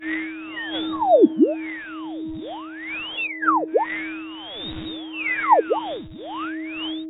detectorFindsSomethin2.wav